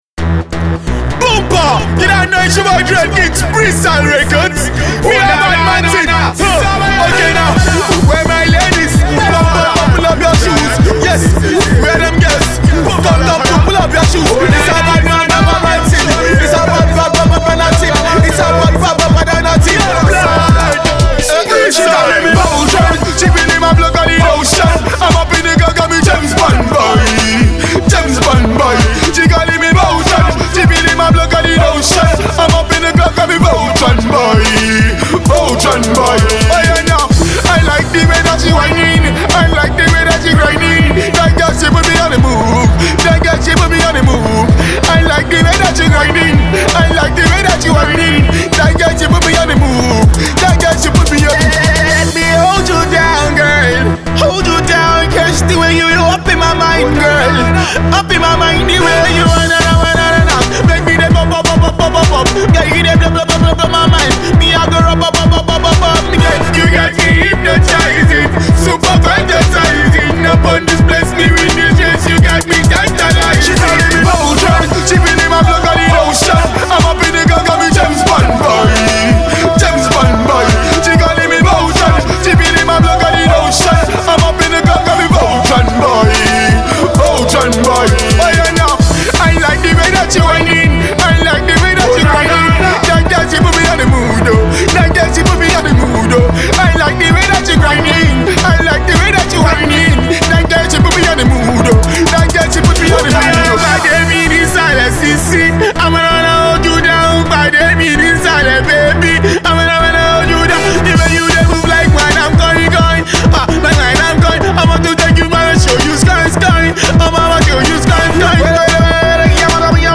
energetic dance-floor-ready Dance Hall tune